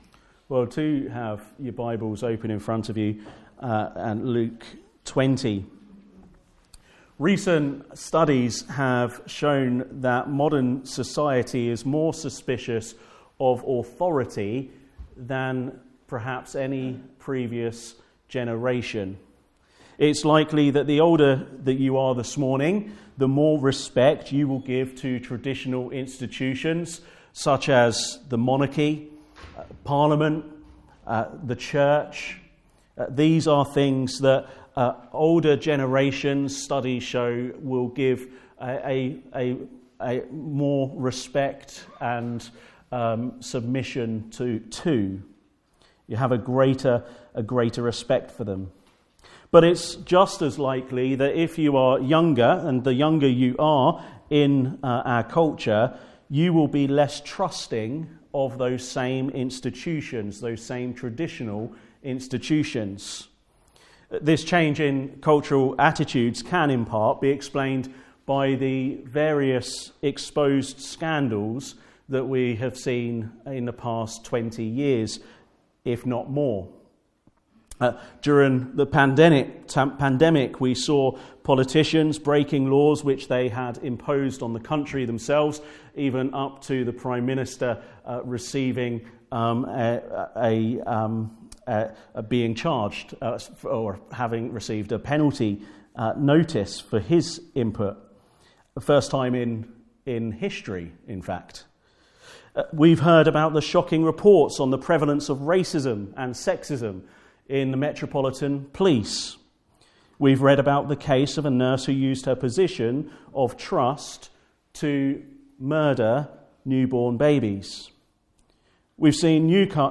Passage: Isaiah 2: 1-11 Service Type: Afternoon Service